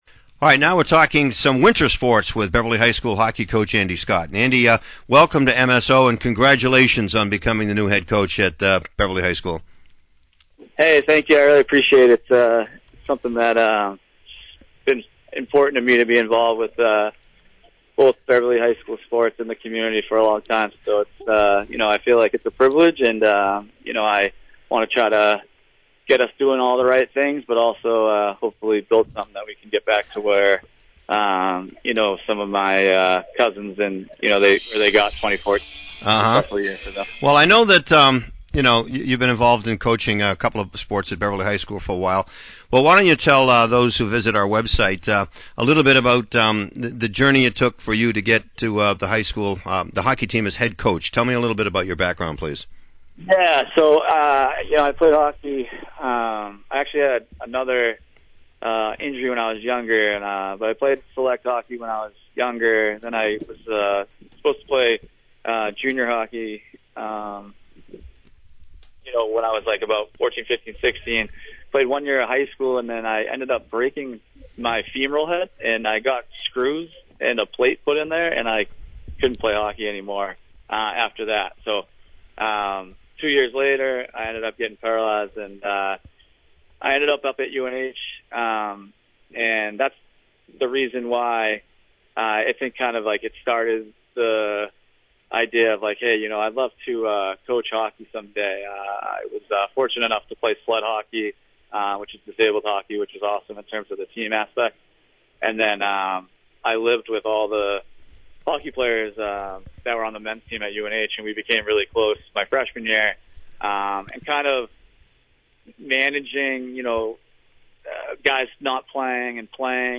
(Audio) High School Boys’ Hockey Preview